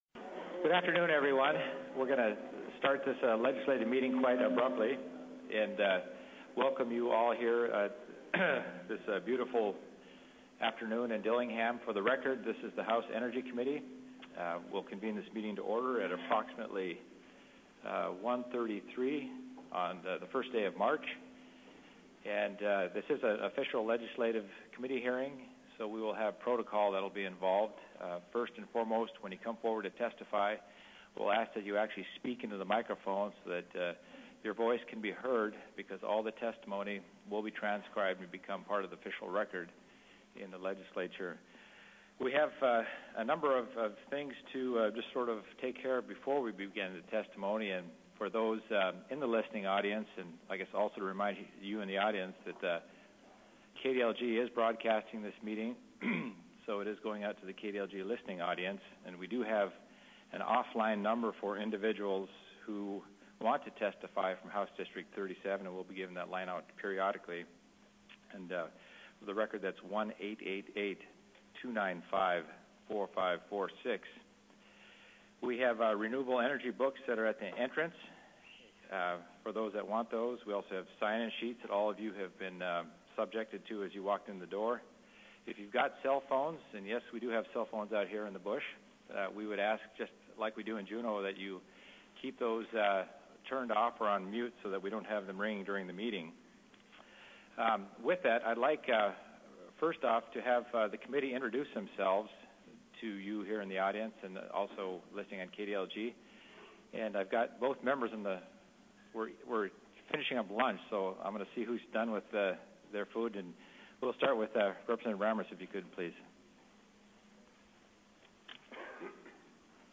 Held in DILLINGHAM Elementary School Gym 711 Seward Street
Public Testimony on Energy Plan